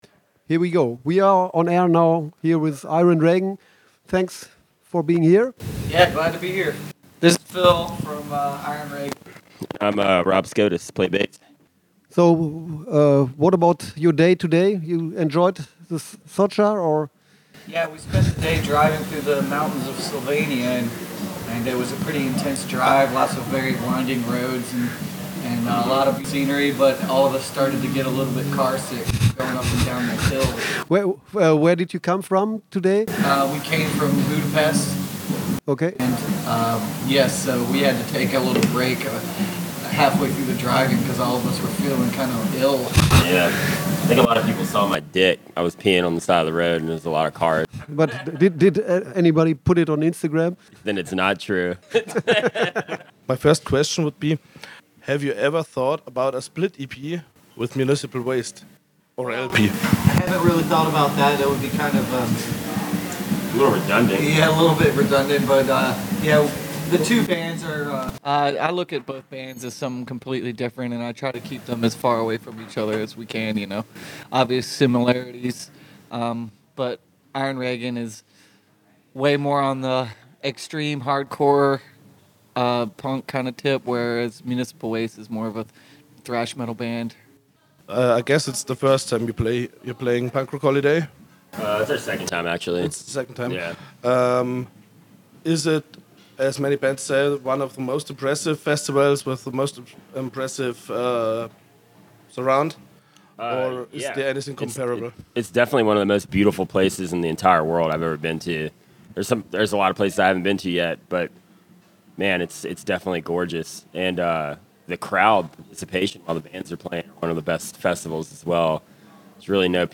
Iron Reagan visited us at our festival radio studio for a short interview.
iron-reagan-interview-punk-rock-holiday-1-9-mmp.mp3